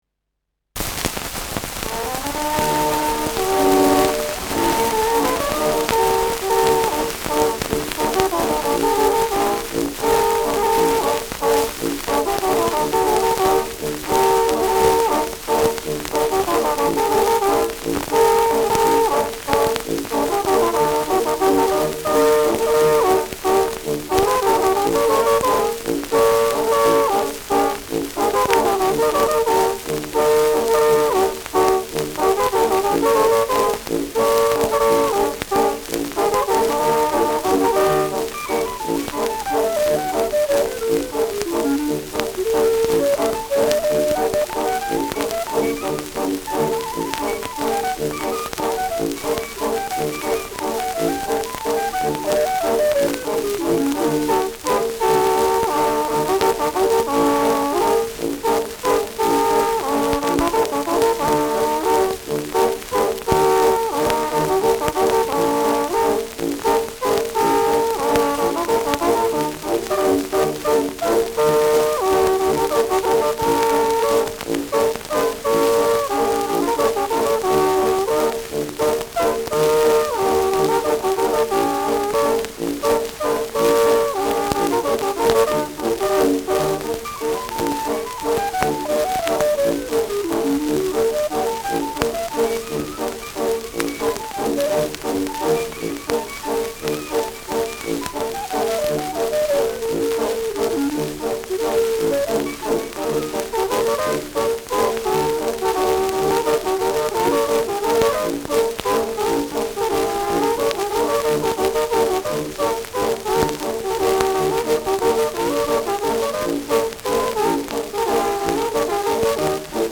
Schellackplatte
starkes Rauschen : starkes Knistern : abgespielt : leiert : präsentes Nadelgeräusch : häufiges Knacken
Dachauer Bauernkapelle (Interpretation)
[München] (Aufnahmeort)